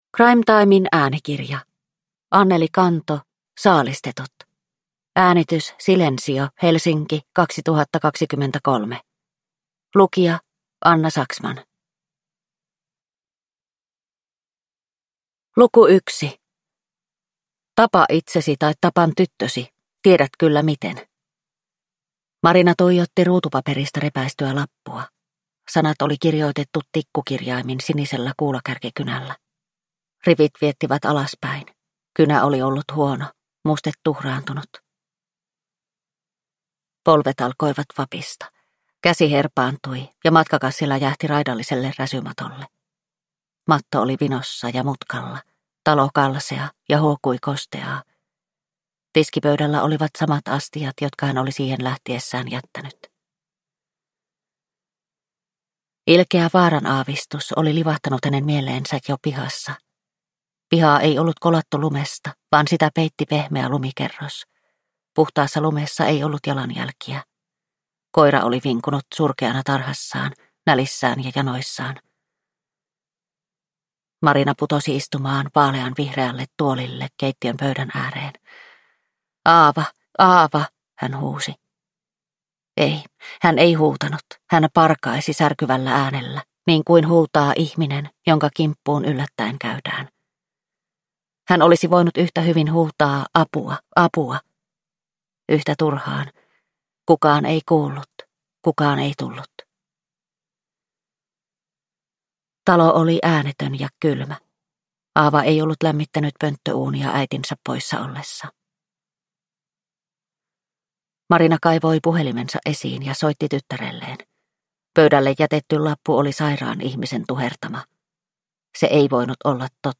Saalistetut – Ljudbok – Laddas ner